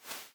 Footstep_Sand.ogg